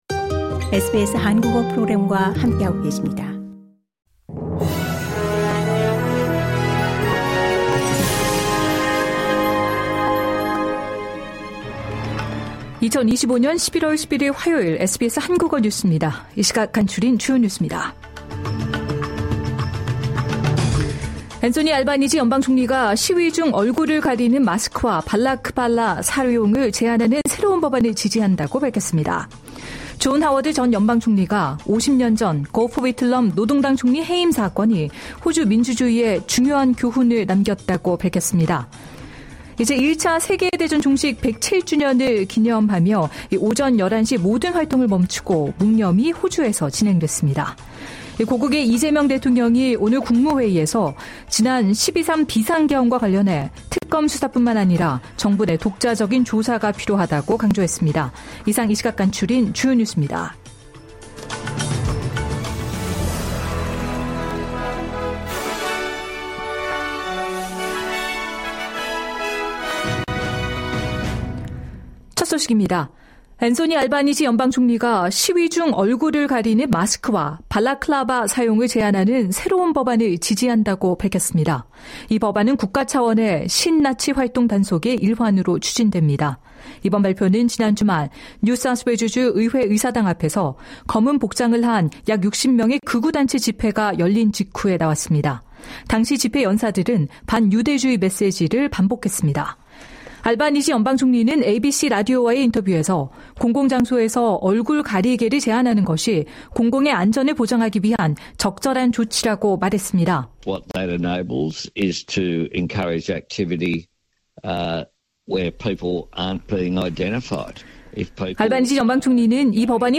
주요 뉴스에서 환율, 내일의 날씨까지. 매일 10분 내로 호주에서 알아야 할 뉴스를 한국어로 정리해 드립니다.